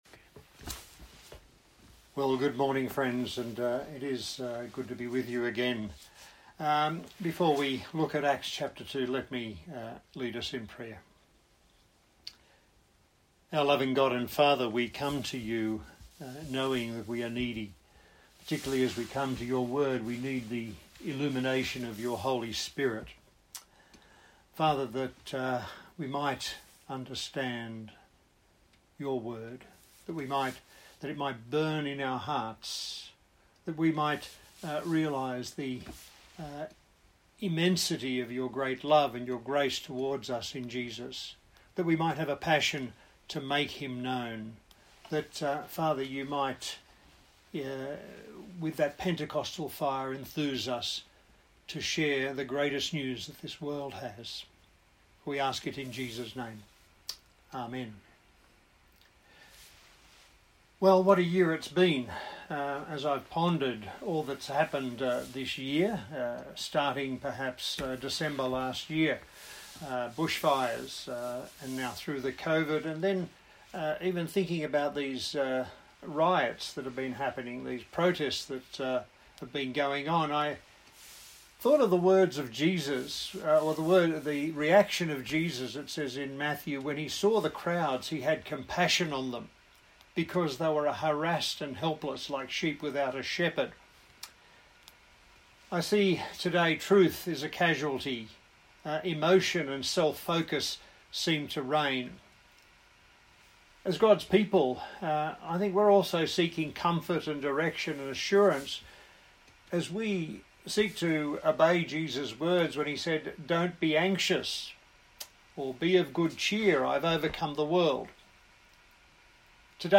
A sermon in the series on the book of Acts